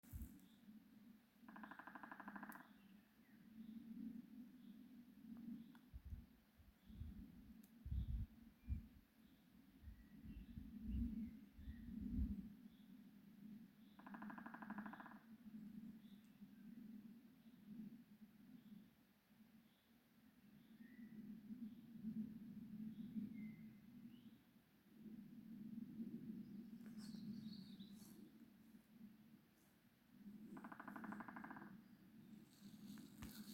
Birds -> Woodpeckers ->
Three-toed Woodpecker, Picoides tridactylus
Notes/izpr. 1min, M, te pat nesekmīgi provocēts ļoti ilgstoši pirms nedēļas